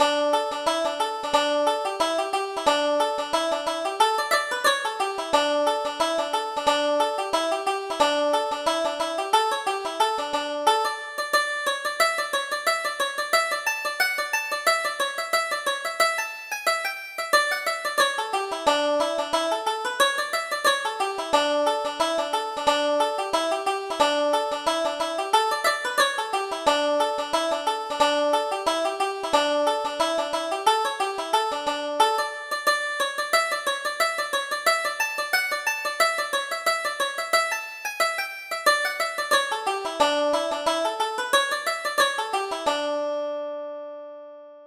Reel: Toss the Feathers - 1st Setting